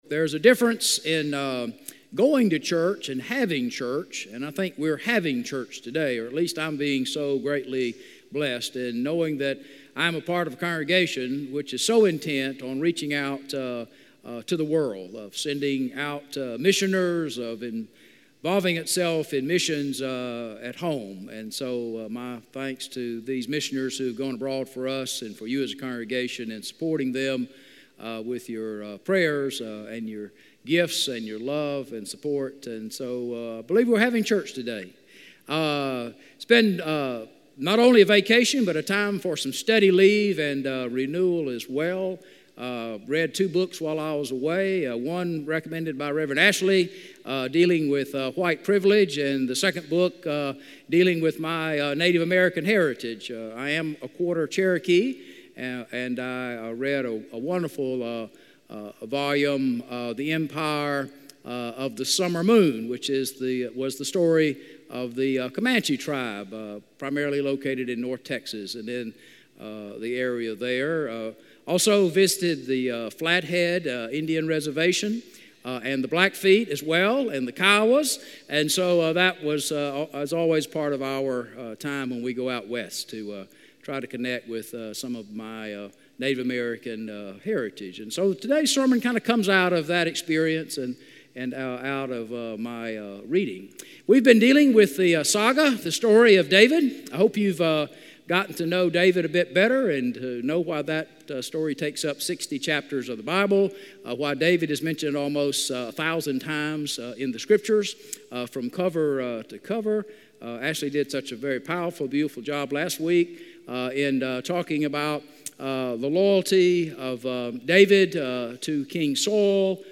A message from the series "The Story of David."